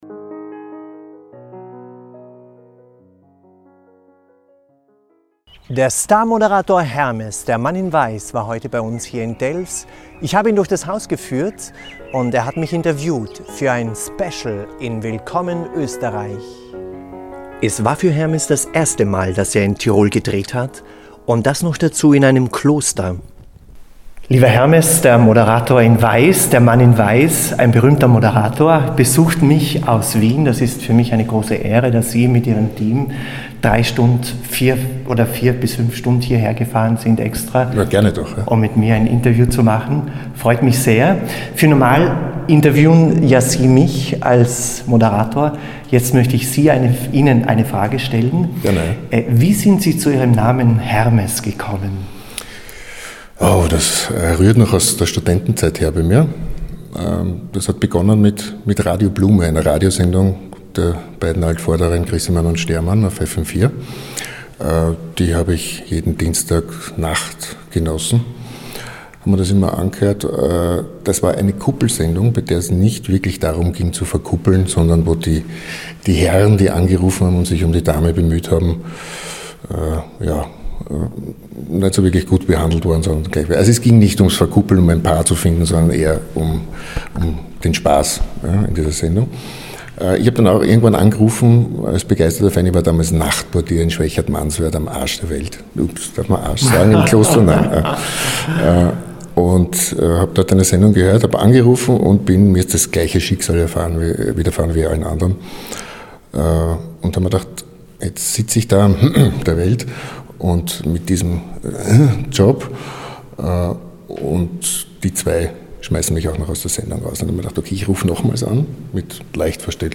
Ich habe ihn interviewt.